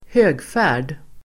Ladda ner uttalet
Uttal: [²h'ö:gfä:r_d (el. ²h'ök:-)]